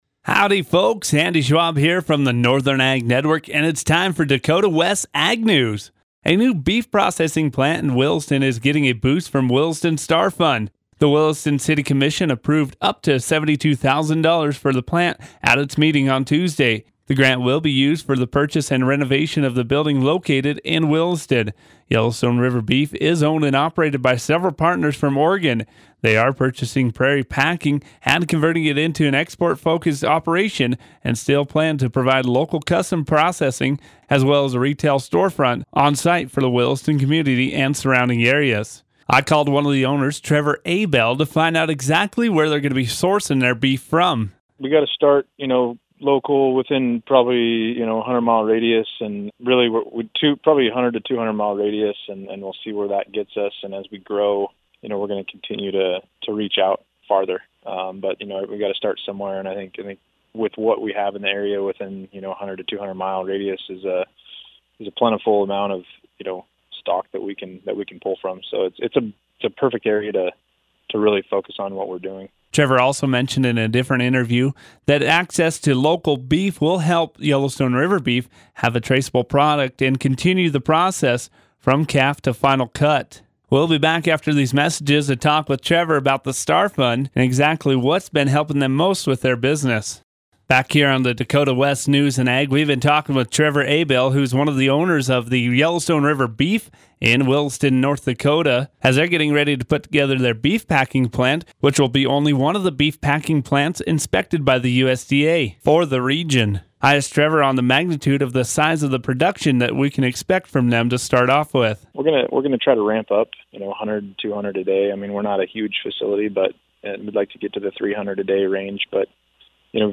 Dakota-West-News-in-Ag-8-16-19.mp3